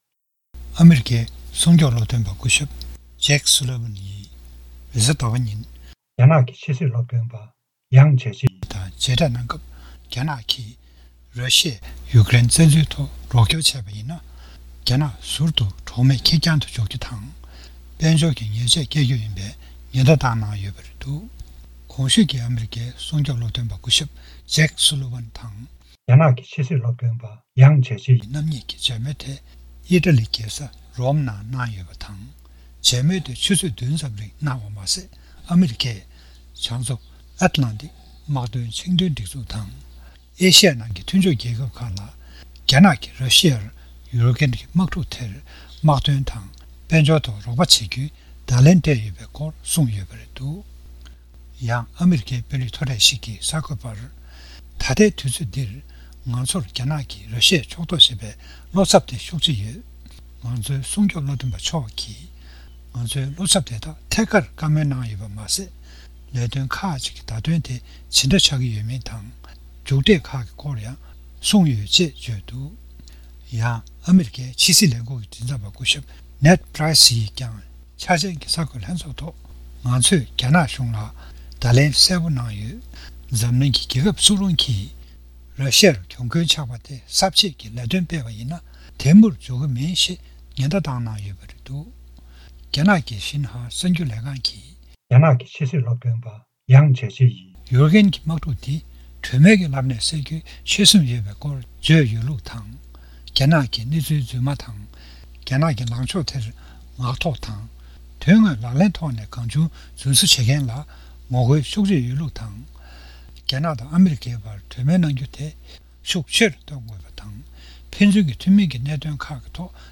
སྒྲ་ལྡན་གསར་འགྱུར། སྒྲ་ཕབ་ལེན།
ཉམས་ཞིབ་པར་བཀའ་འདྲི་ཞུས་པ།